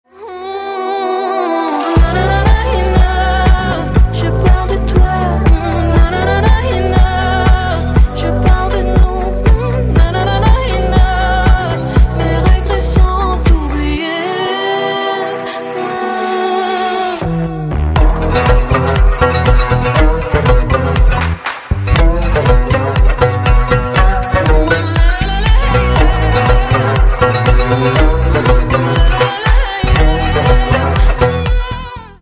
# Поп Рингтоны